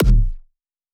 • HardHouse Kick HARD 21 - Bm.wav